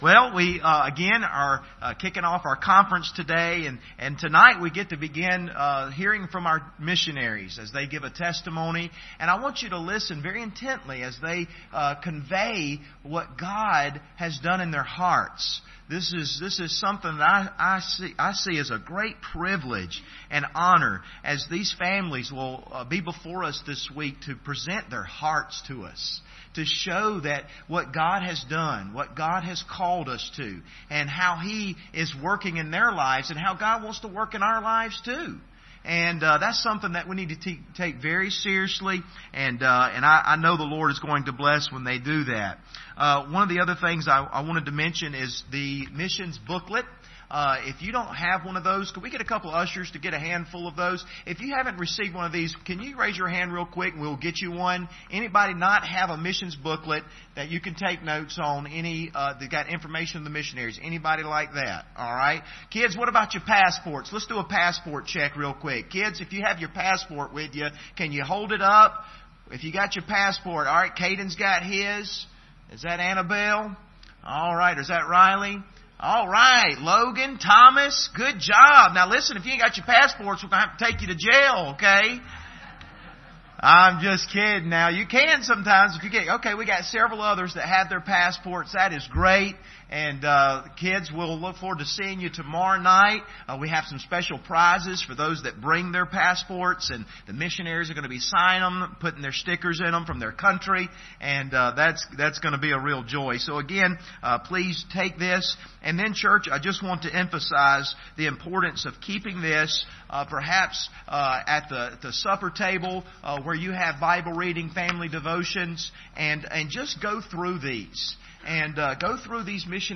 Missionary Presentation
Service Type: Special Service